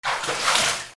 水音 水をかける
/ M｜他分類 / L30 ｜水音-その他